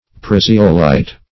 Search Result for " praseolite" : The Collaborative International Dictionary of English v.0.48: Praseolite \Pra"se*o*lite\, n. [Praseo- + -lite.]